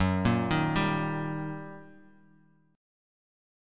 Eb/Gb chord
Eb-Major-Gb-2,1,1,0,x,x-8.m4a